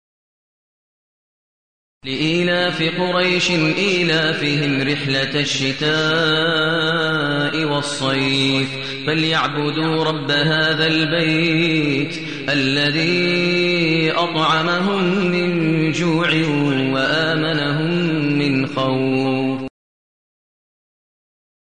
المكان: المسجد النبوي الشيخ: فضيلة الشيخ ماهر المعيقلي فضيلة الشيخ ماهر المعيقلي قريش